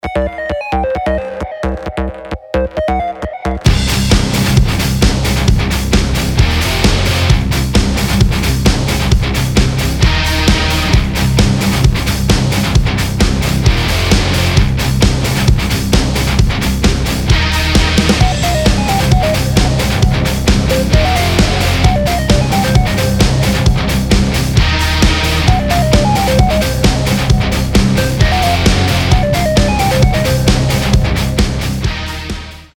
• Качество: 320, Stereo
громкие
мощные
брутальные
Драйвовые
без слов
Industrial metal
Neue Deutsche Harte
Мощная метал-музыка для любителей звонка потяжелее